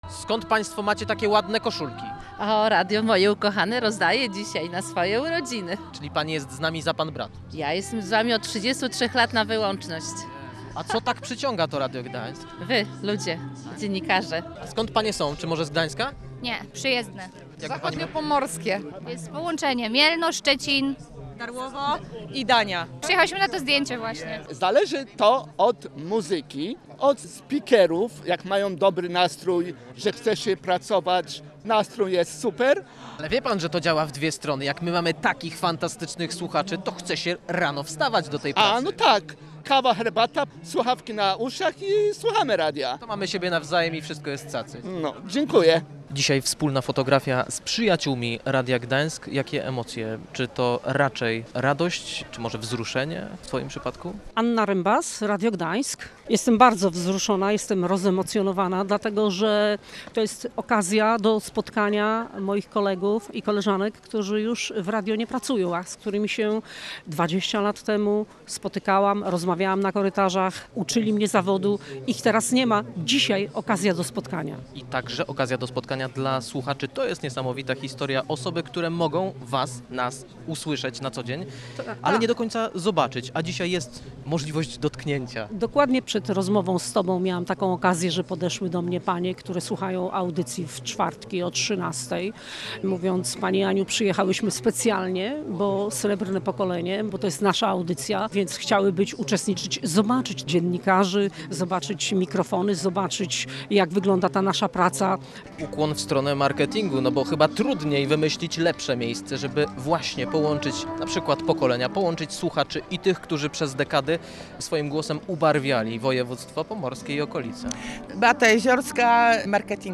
Zgodnie z naszą zapowiedzią było barwnie, wesoło, sentymentalnie i momentami wzruszająco. 10 maja setki osób odwiedziły nasze plenerowe studio, a w samo południe słuchacze zameldowali się obok Fontanny Neptuna w Gdańsku, by razem z nami wziąć udział w pamiątkowym zdjęciu przyjaciół Radia Gdańsk.